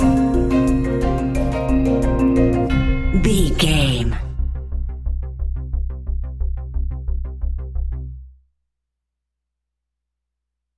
Ionian/Major
A♯
electronic
techno
trance
synths
synthwave
instrumentals